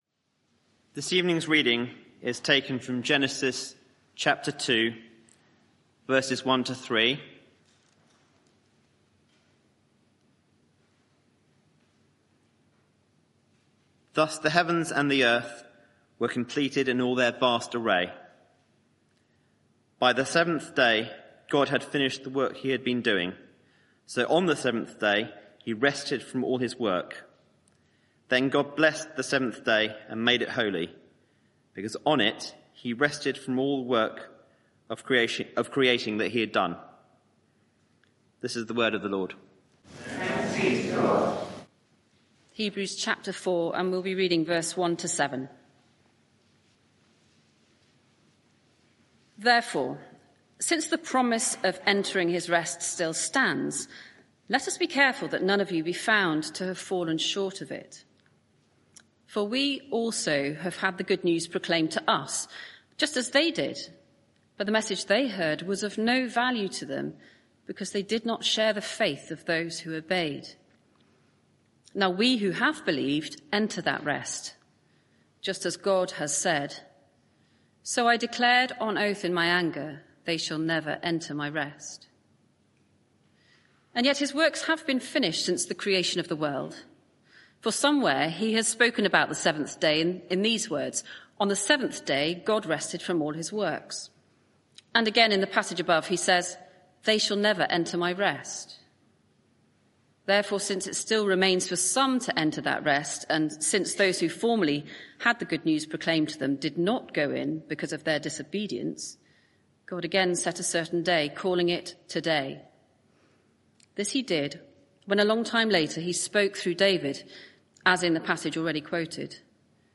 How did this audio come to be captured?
Media for 6:30pm Service on Sun 13th Oct 2024 18:30 Speaker